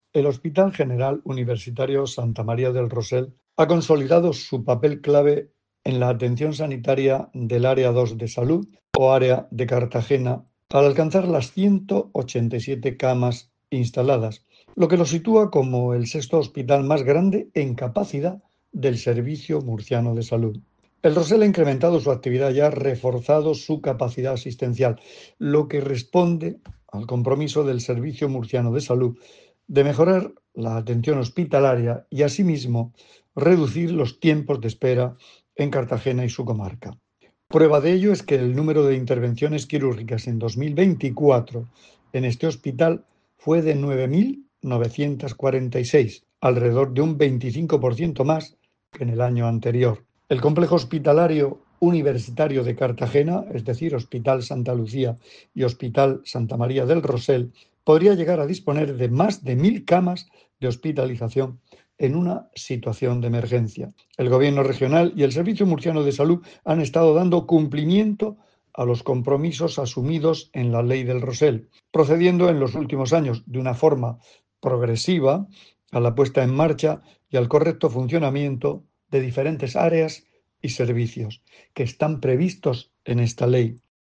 Declaraciones del consejero de Salud, Juan José Pedreño, sobre la actividad del hospital Santa María del Rosell [mp3]